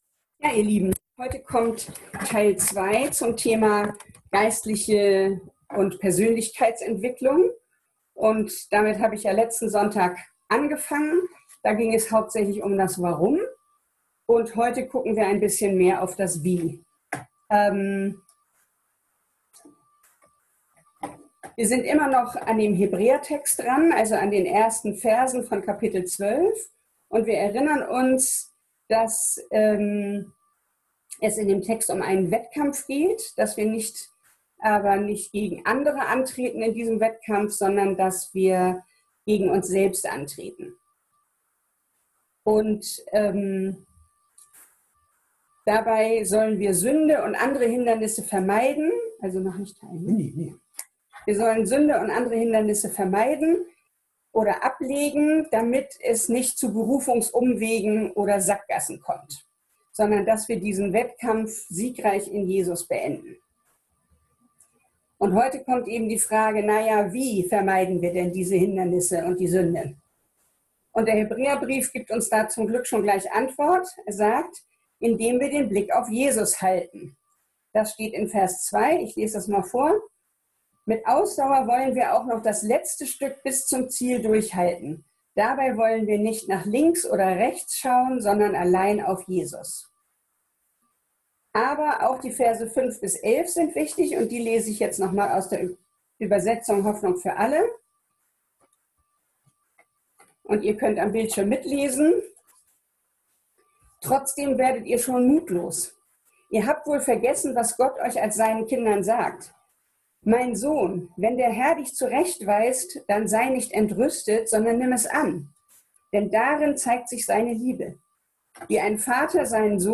Serie: Predigt Gottesdienst: Sonntag %todo_render% « Gesitliche und persönliche Entwicklung